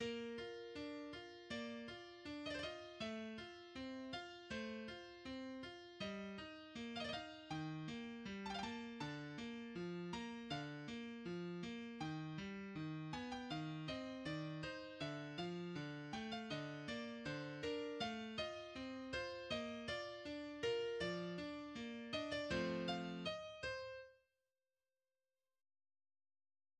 фортепиано
Главной тональностью второй части является си-бемоль мажор (в среднем разделе мелодия модулирует в си-бемоль минор).